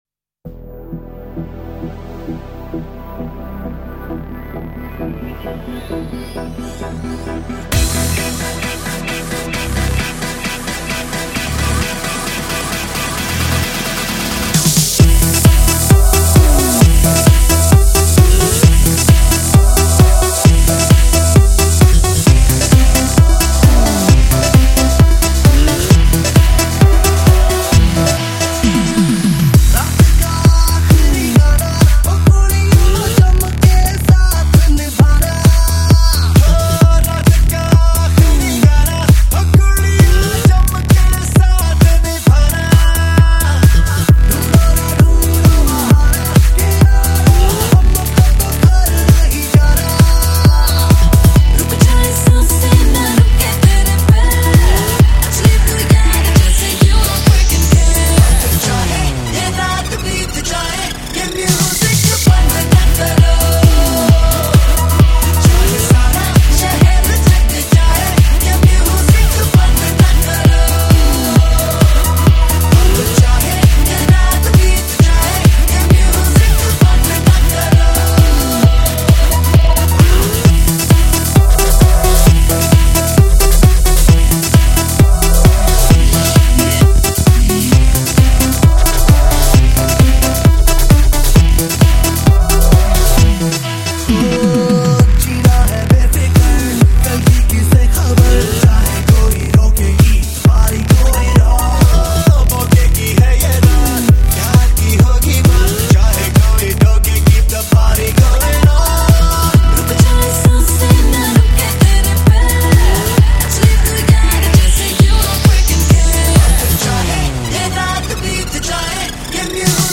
آهنگ محلی شاد هندی
Happy Local Song of Indian